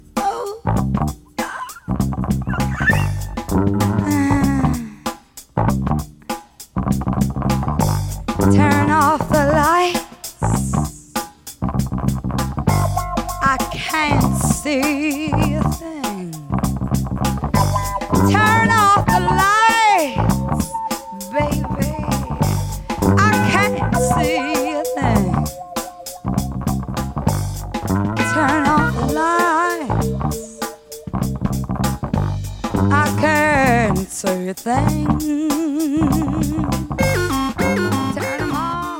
Vocal sexy soul gem!!